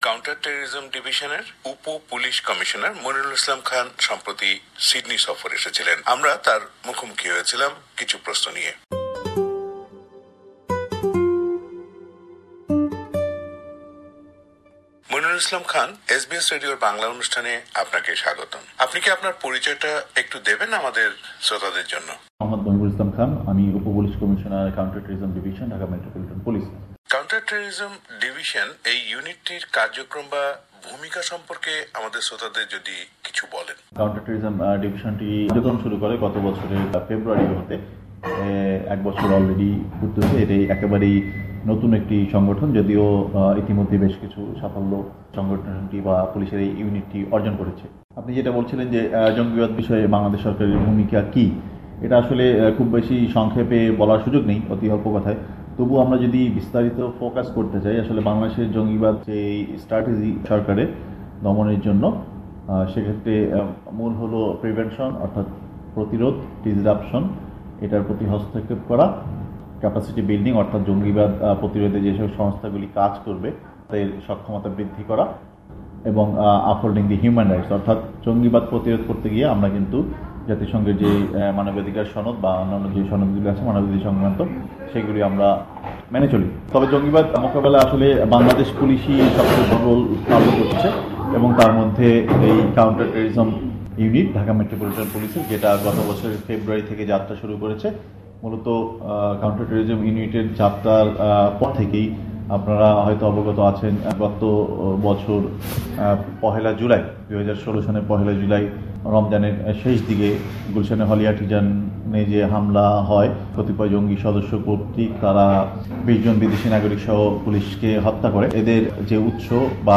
Interview with Monirul Islam Khan Deputy Police Commissioner counterterrorism unit of Bangladesh Police